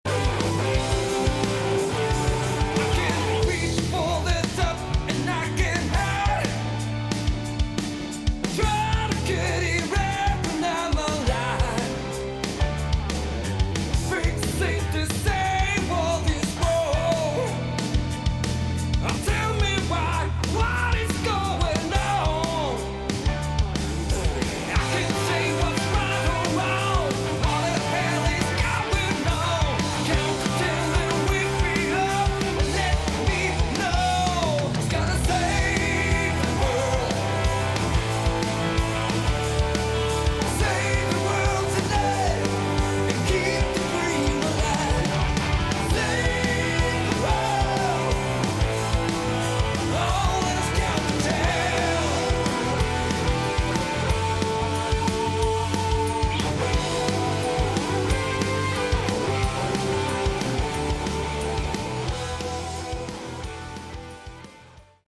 Category: AOR / Melodic Rock
Vocals, Guitars
Bass
Drums
Keyboards